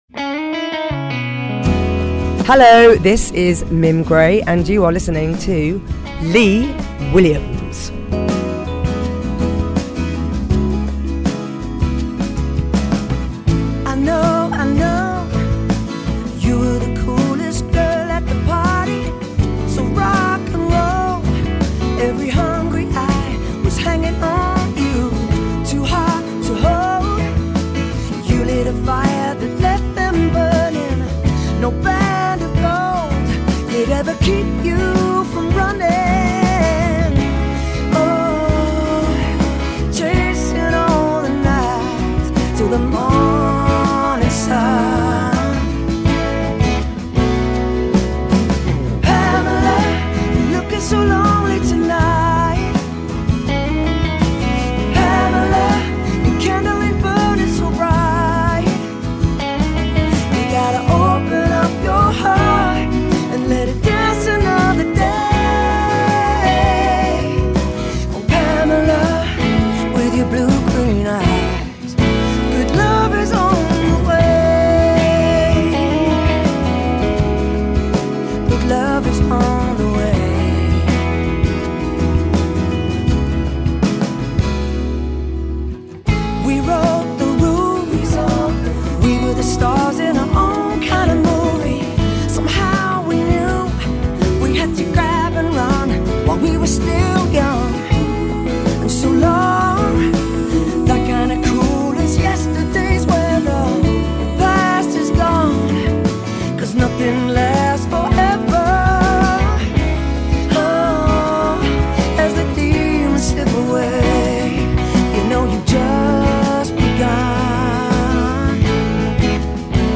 Weyfest Music Festival